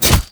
killing_shot2.wav